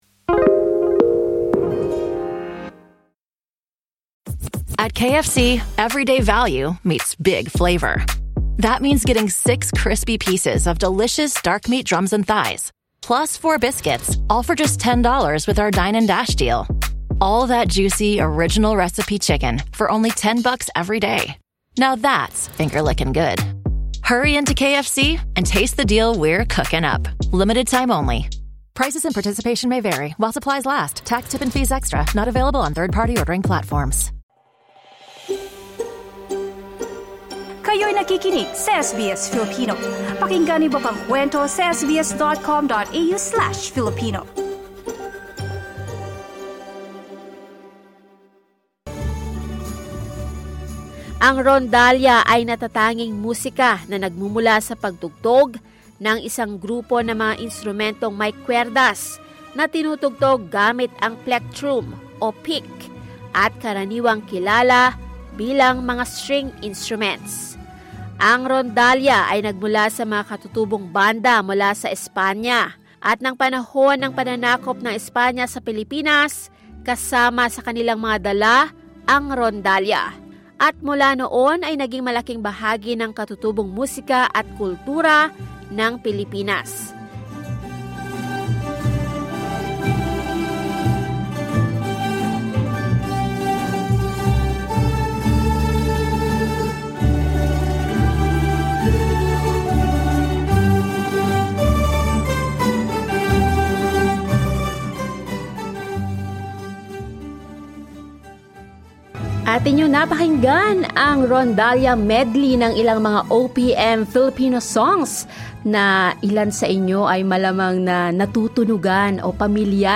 Starting as young as eight years old, this group of Filipino students has come to appreciate the beauty and cultural significance of the rondalla—a traditional string ensemble they proudly play to promote Filipino heritage in performances abroad.
Showing the Filipino string instruments, the De La Salle Zobel Rondalla from the Philippines brings its sounds and music to the 33rd Australian International Music Festival this July.